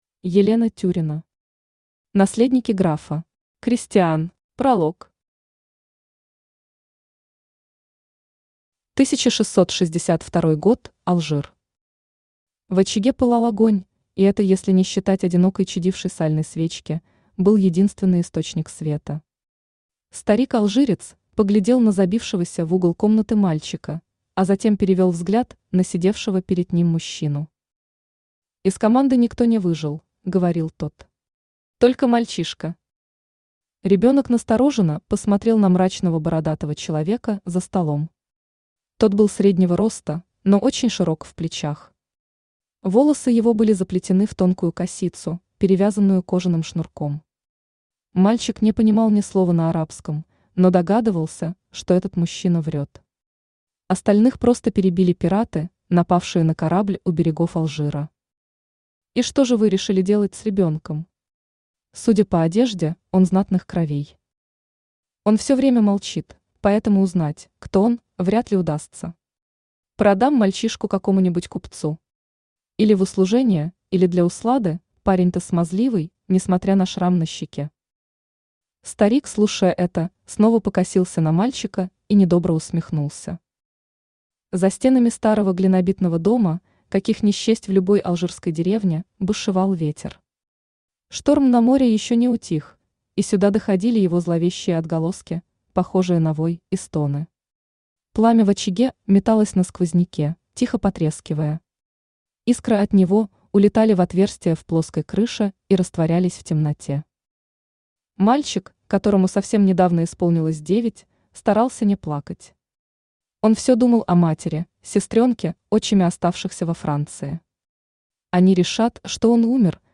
Аудиокнига Лучник | Библиотека аудиокниг
Aудиокнига Лучник Автор Елена Андреевна Тюрина Читает аудиокнигу Авточтец ЛитРес.